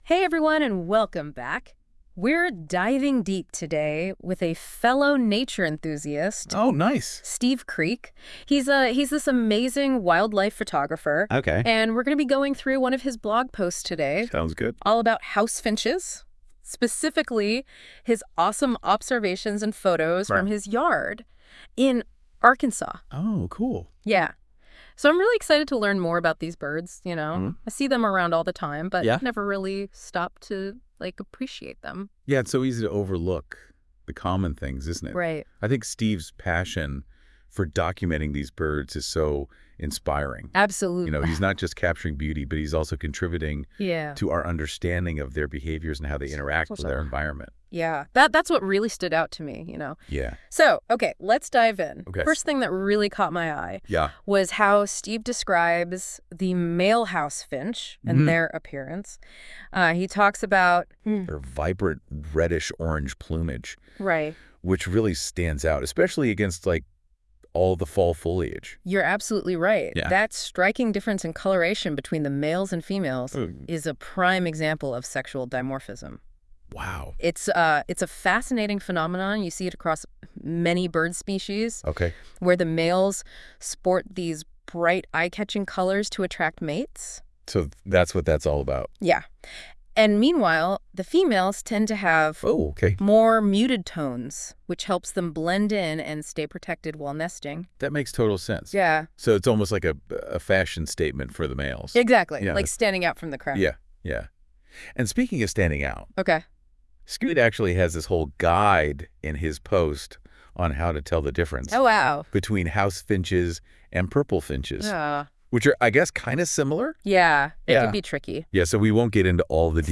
Among them, the House Finches have been particularly captivating, filling the autumn air with their lively calls and adding vibrant splashes of color to the changing landscape.
House-Finch.wav